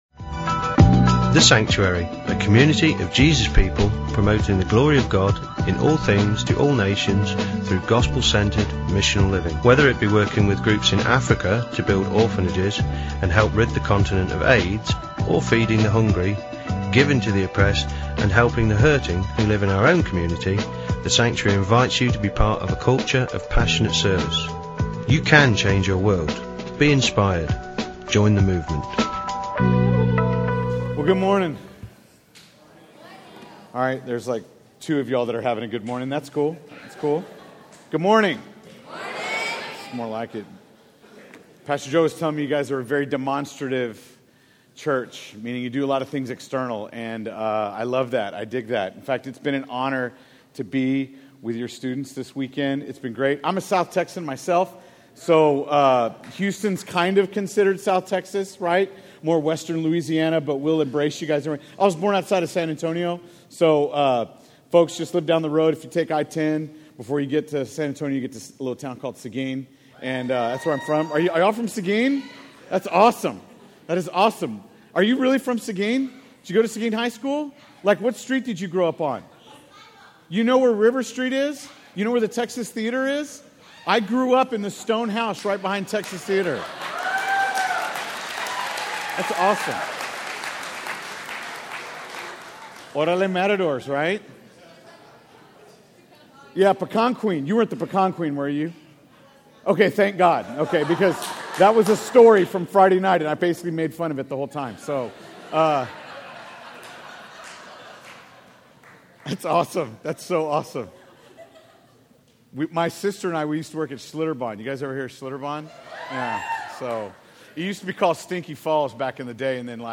A message from the series "Stand-alone Messages 2016."